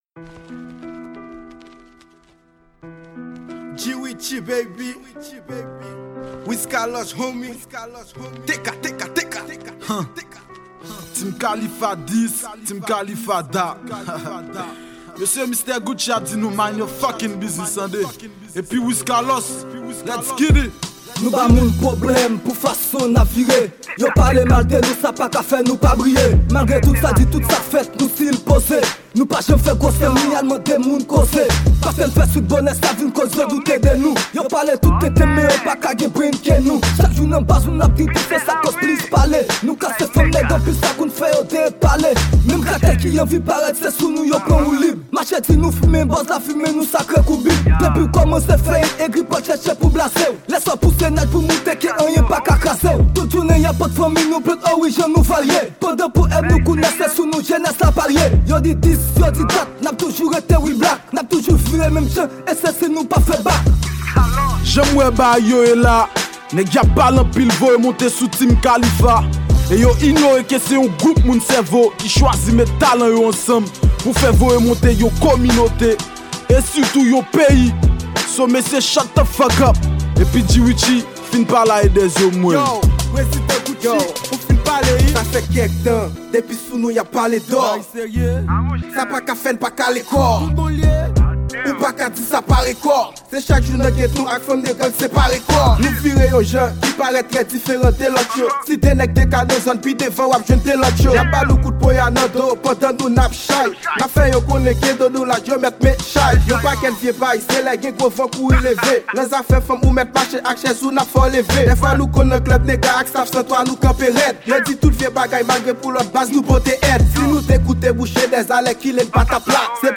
Genre: RA;P.